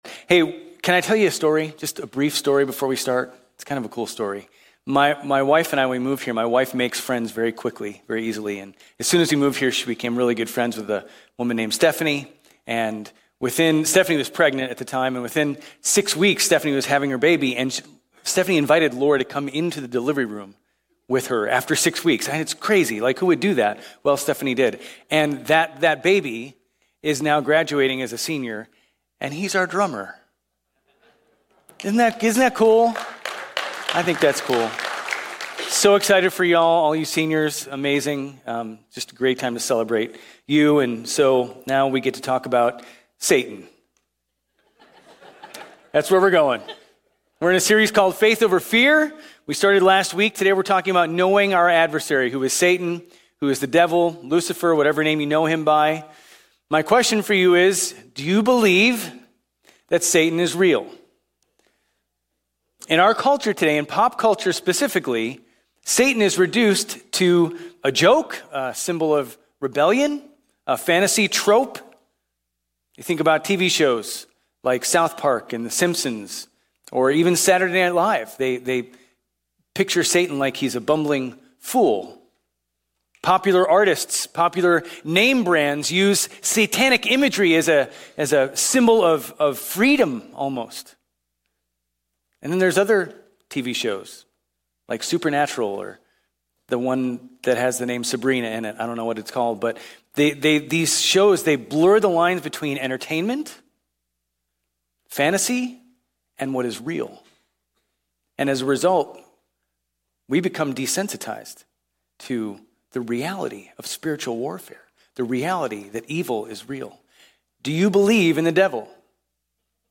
Grace Community Church Old Jacksonville Campus Sermons 5_4 Old Jacksonvlle Campus May 05 2025 | 00:33:43 1x 00:00 / 00:33:43 Subscribe Share RSS Feed Share Link Embed